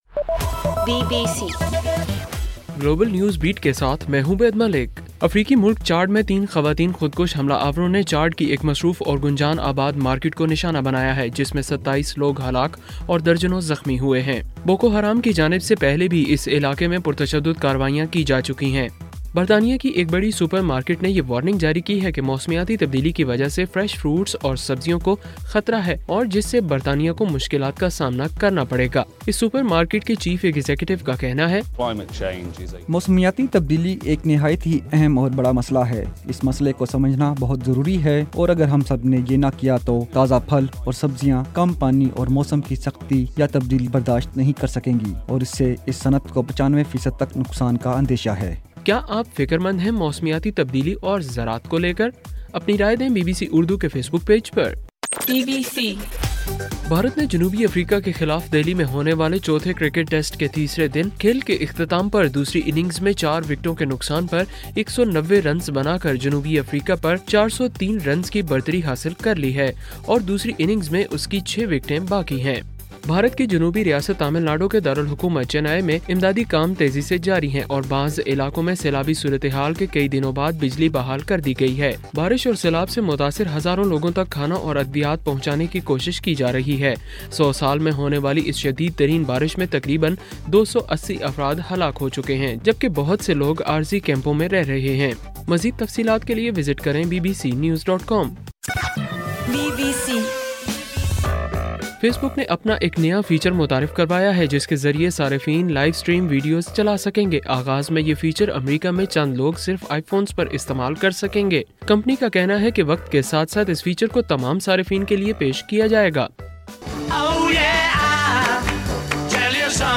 دسمبر 6: صبح 1 بجے کا گلوبل نیوز بیٹ بُلیٹن